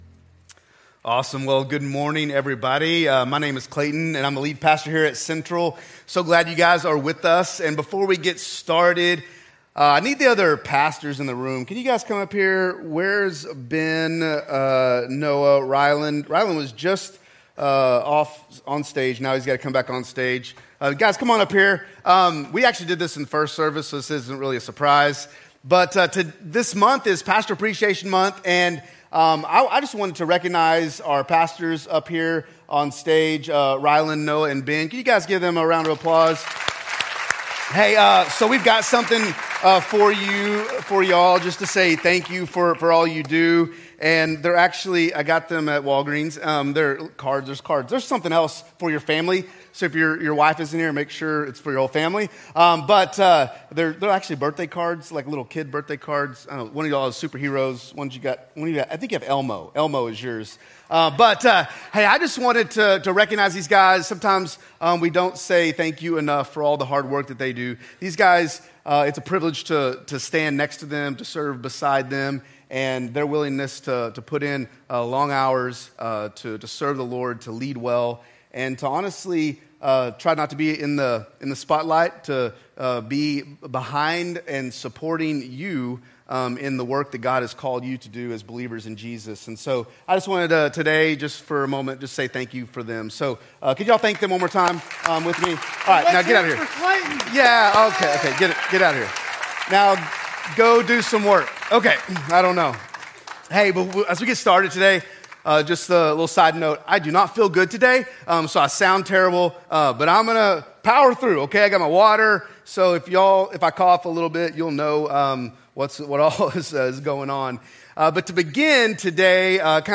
Download Watch Listen Details Share Sermon Notes From Series